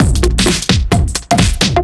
OTG_DuoSwingMixB_130b.wav